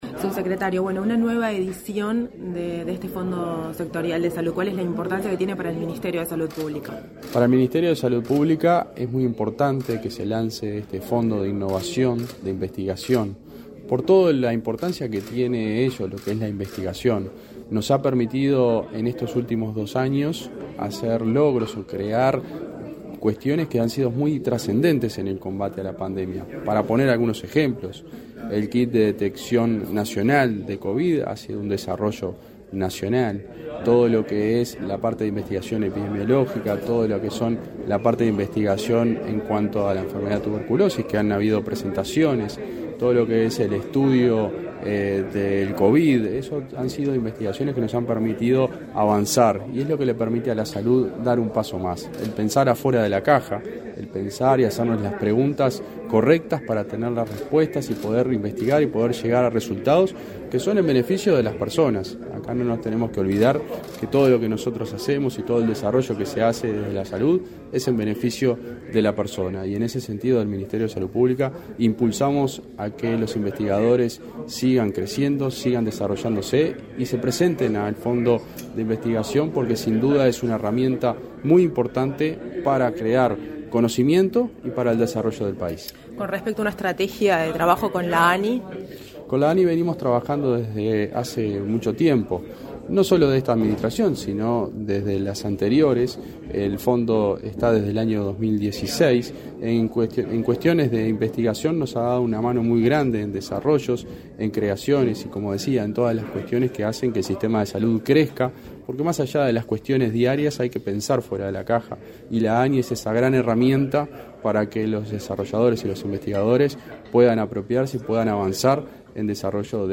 Declaraciones a la prensa del subsecretario de Salud Pública, José Luis Satdjian